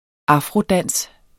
Udtale [ ˈɑfʁo- ]